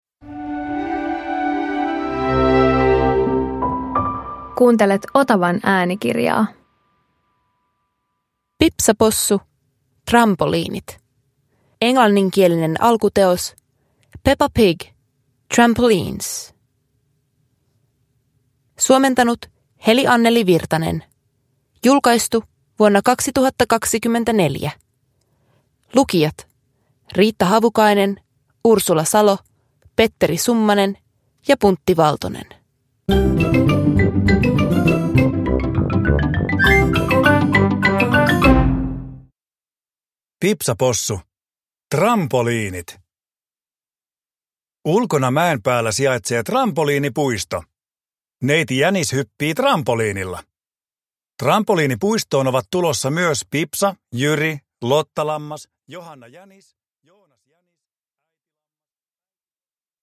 Pipsa Possu - Trampoliinit – Ljudbok